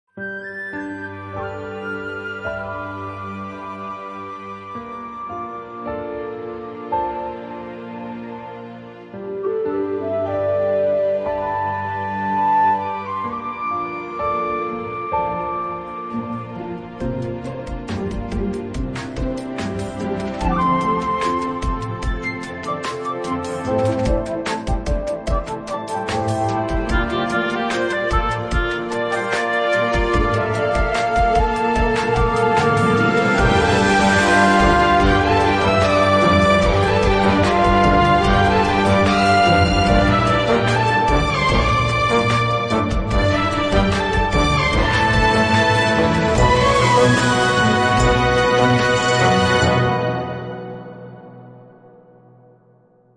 影视原声